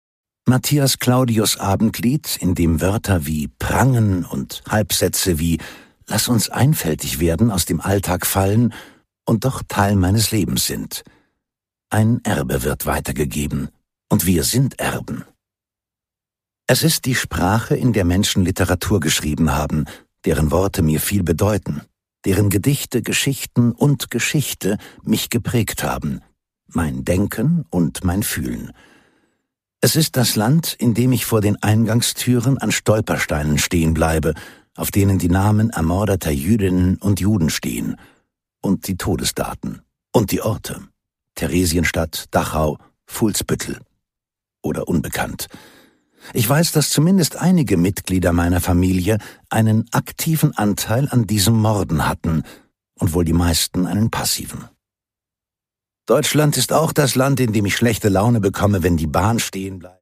Robert Habeck: Den Bach rauf - Eine Kursbestimmung (Ungekürzte Lesung)
Produkttyp: Hörbuch-Download